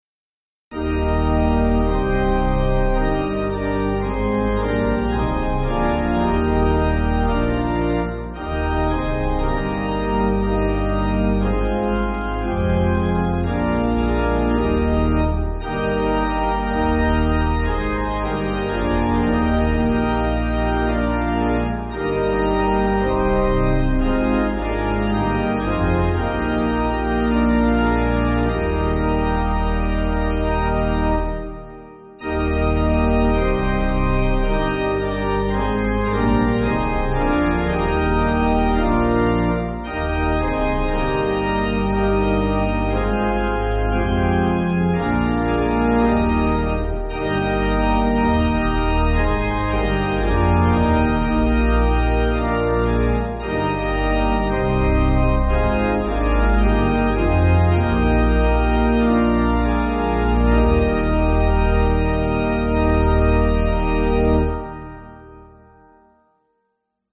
Organ
(CM)   2/Eb